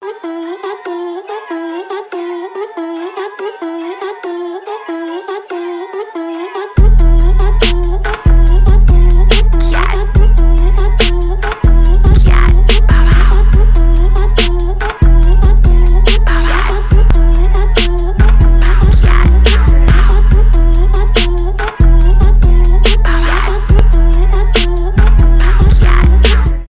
trap
рэп , басы